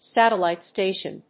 アクセントsátellite stàtion